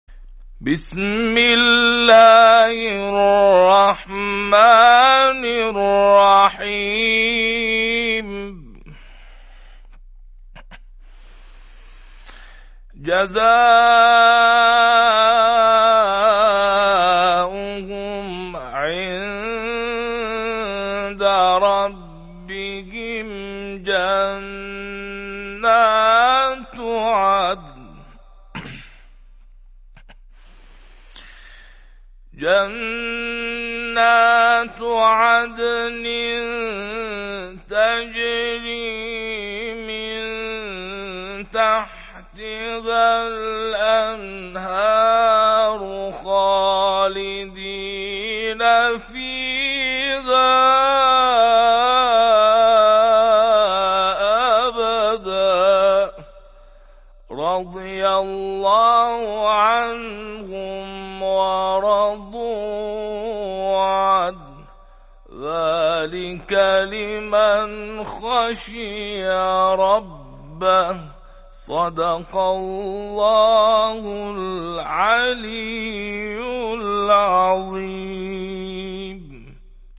در پایان هدیه معنوی این قاری و‌ مدرس قرآن کریم از آیه ۸ سوره بینه به مخاطبان تقدیم می‌شود.